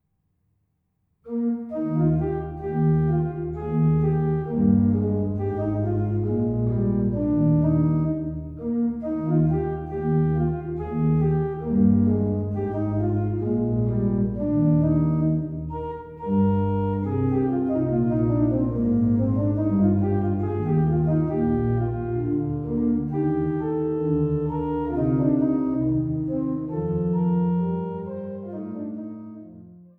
Glatter-Götz-/Rosales-Orgel im Remter des Magdeburger Domes